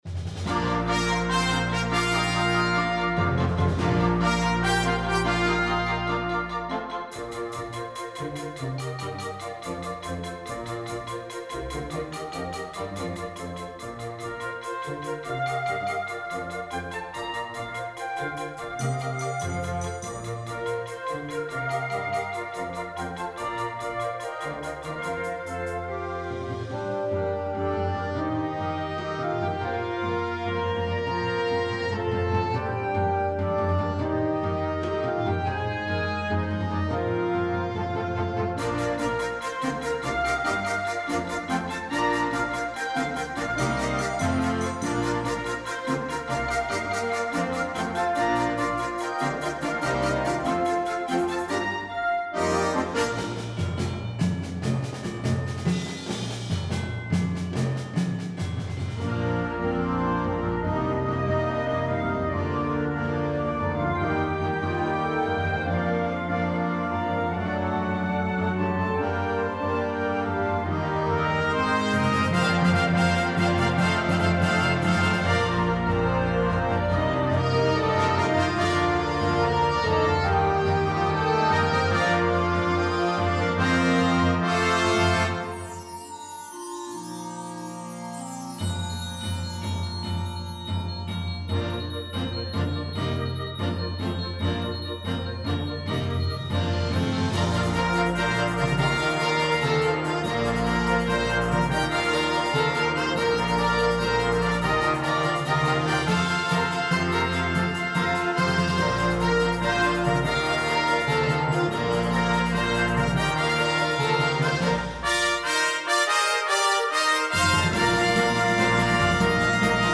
Besetzung: Blasorchester
Genre: Eröffnungsmusik